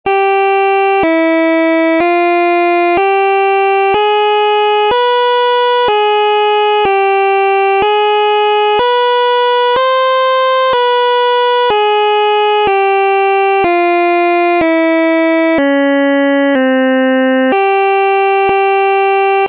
Κλίμακα
Οἱ ἤχοι ἔχουν παραχθεῖ μὲ ὑπολογιστὴ μὲ ὑπέρθεση ἀρμονικῶν.